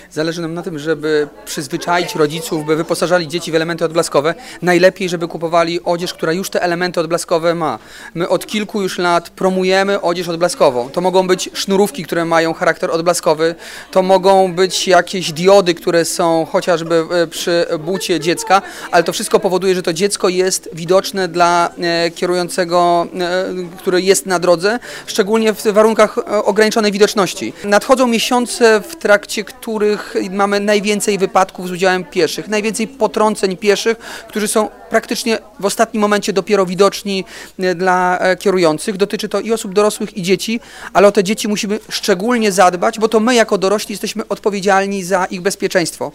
Mówił Rzecznik Prasowy Komendanta Głównego Policji inspektor Mariusz Sokołowski.
1_rzecznik_policji_KGP.mp3